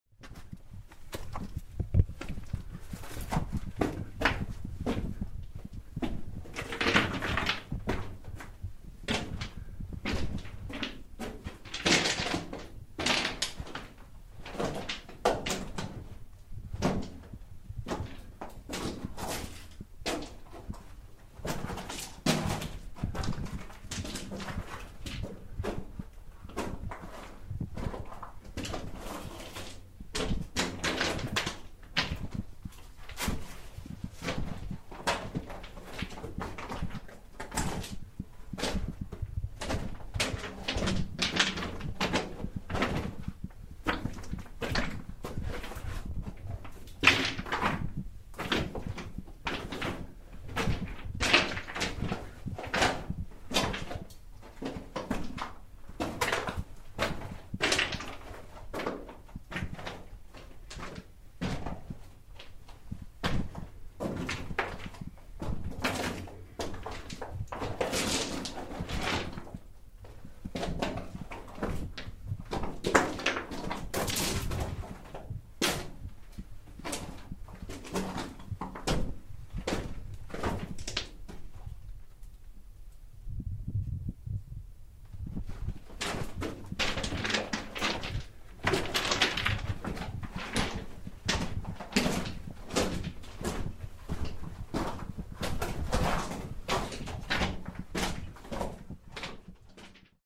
Inside a mountain war bunker
Facebook Twitter Headliner Embed Embed Code See more options This recording is from inside one of the many shelters carved into Monte Grappa, northern Italy, during World War 1, as soldiers cleared and protected a supply line through the mountains.